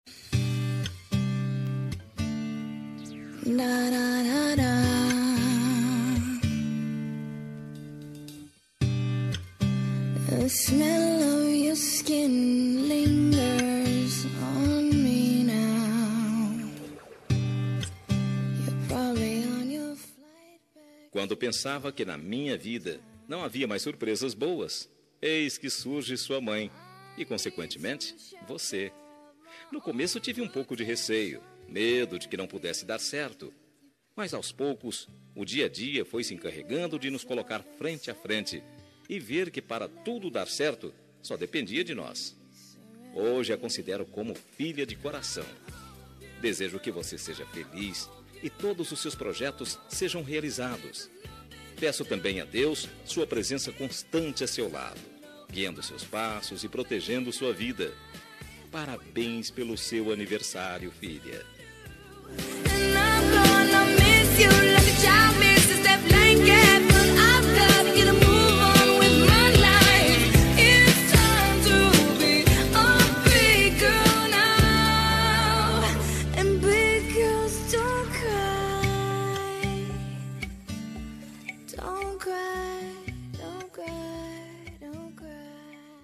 Aniversário de Enteada – Voz Masculina – Cód: 4038